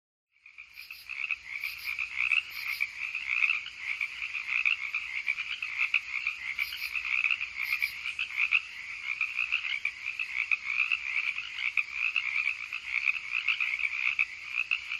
Frog Pond
Frog Pond is a free animals sound effect available for download in MP3 format.
# frog # pond # night About this sound Frog Pond is a free animals sound effect available for download in MP3 format.
041_frog_pond.mp3